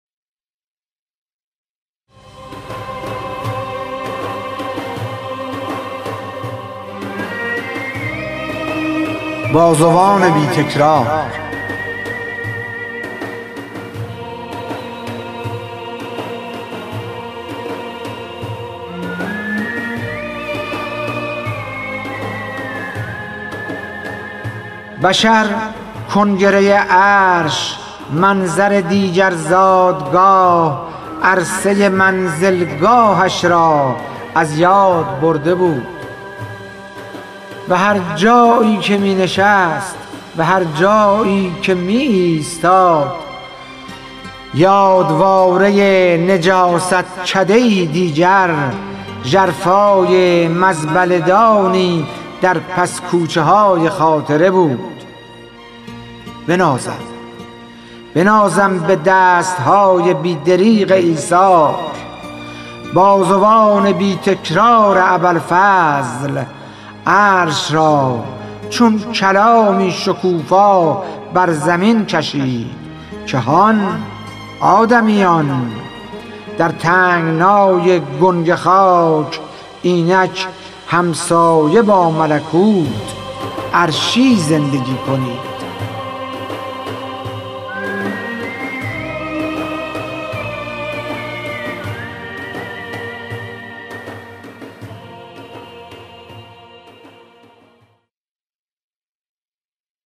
خوانش شعر سپید عاشورایی / ۳